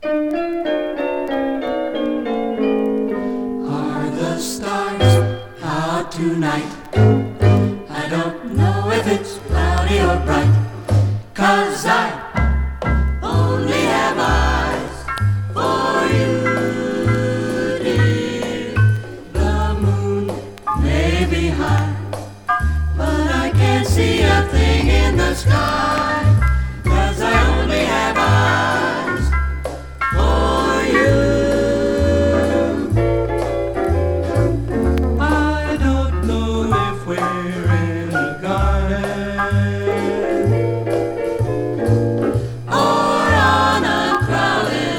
男女混声ヴォーカルグループ
Jazz, Pop, Vocal, Easy Listening　USA　12inchレコード　33rpm　Stereo